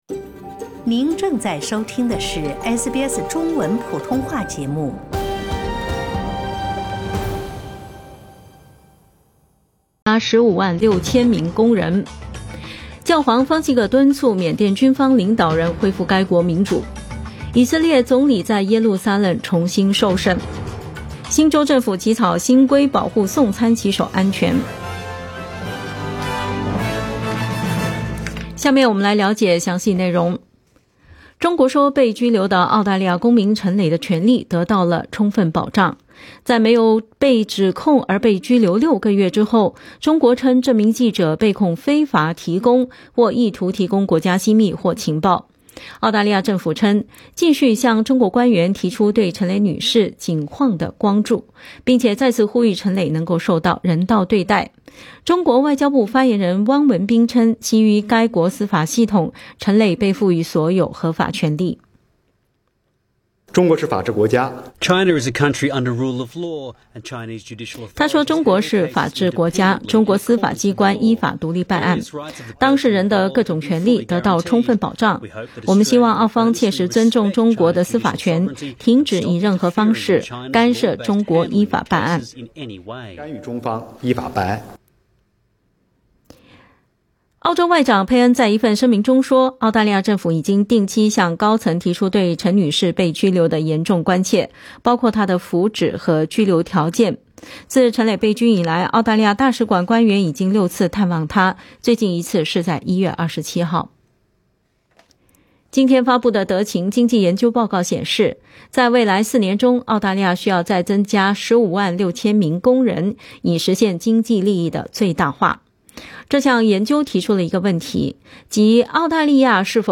SBS早新聞（2月9日）